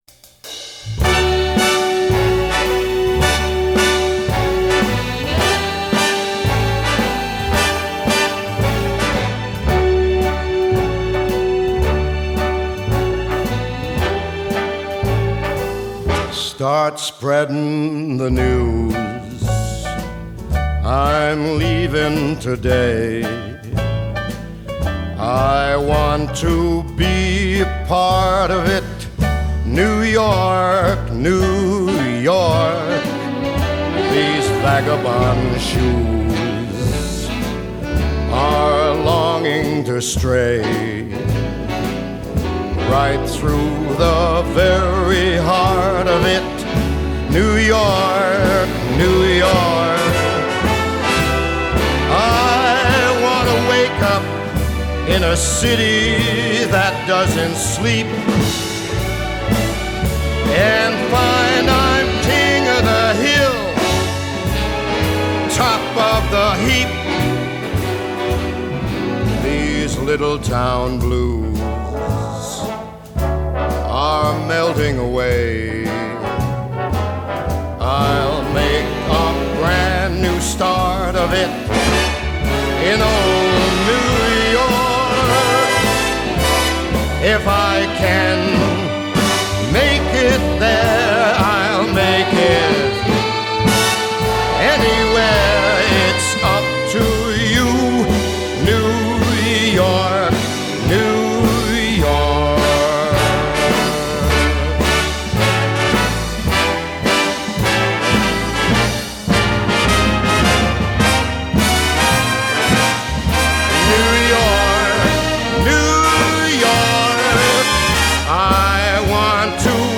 Jazz, Pop